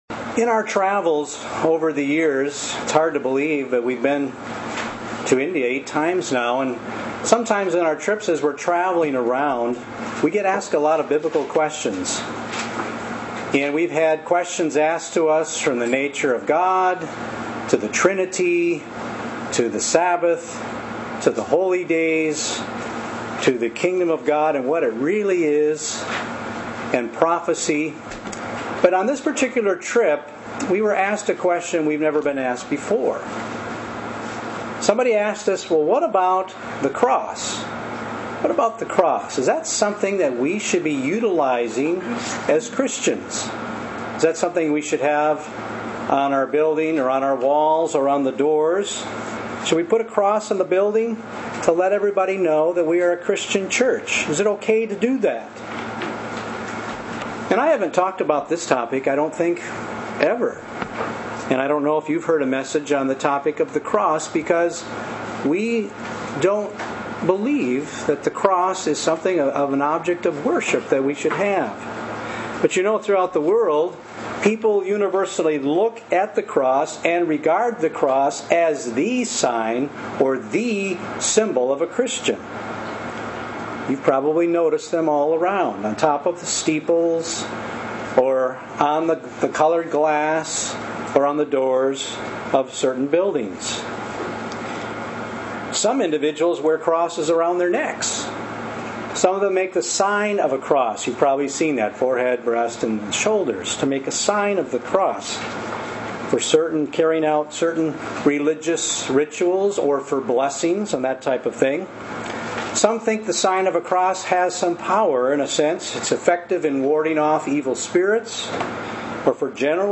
This sermon reviews this issue and refers to the scriptures and historical writings in addressing the question: Should true Christians use the cross in worship?